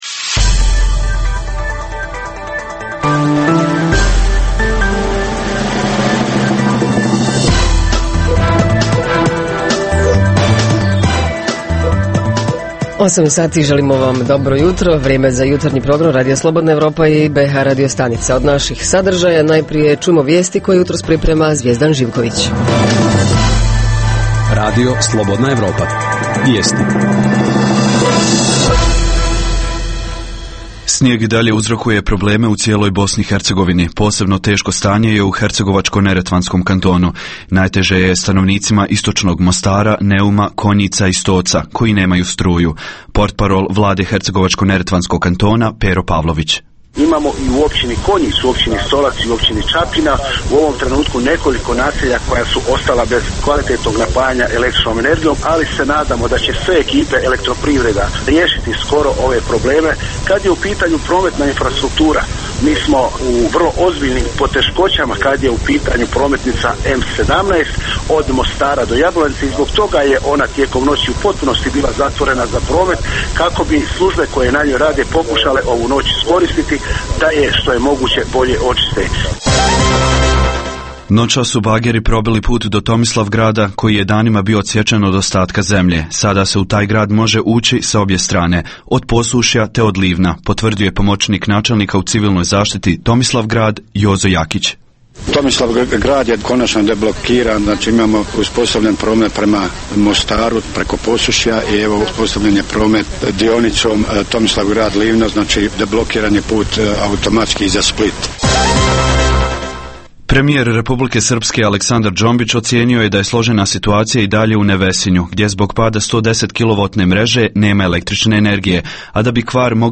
Jutros ćemo saznati kako se odvija život na području Banja Luke, Doboja, Travnika i Jablanice. - Redovna rubrika: Filmoskop Redovni sadržaji jutarnjeg programa za BiH su i vijesti i muzika.